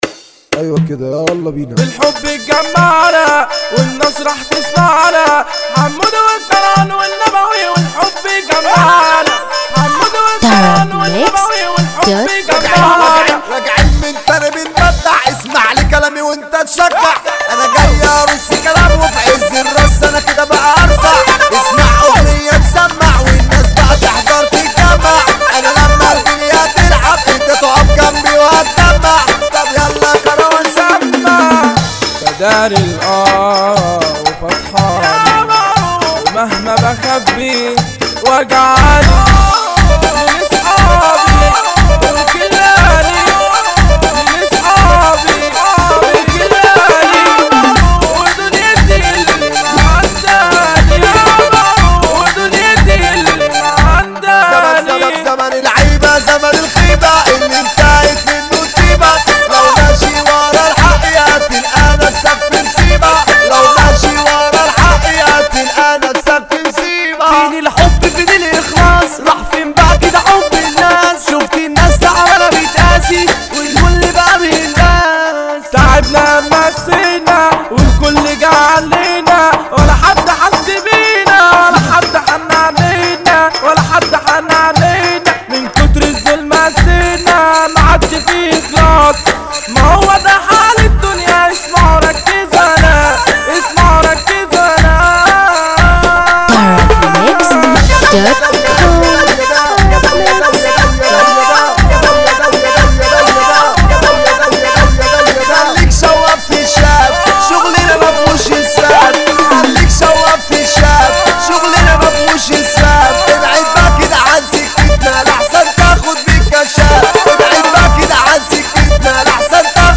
مهرجان